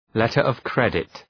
Leave a reply letter of credit Dëgjoni shqiptimin https